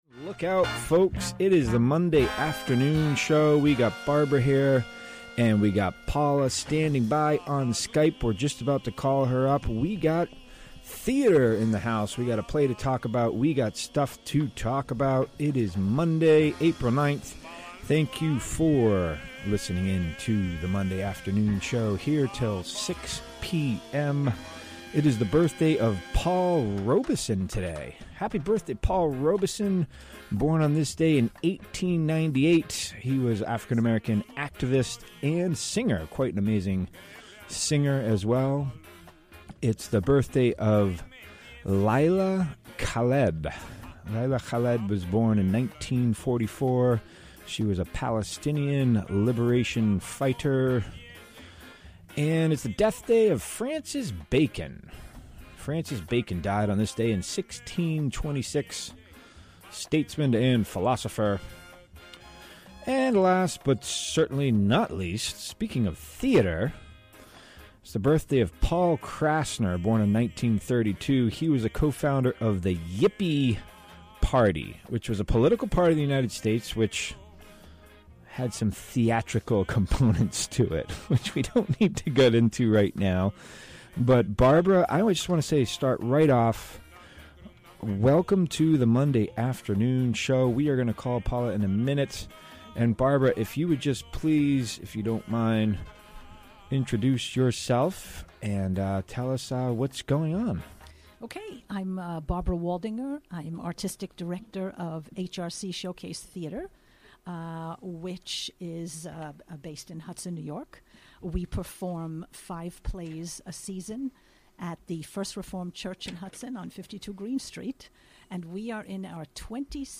Recorded live on the WGXC Afternoon show on April 9, 2018.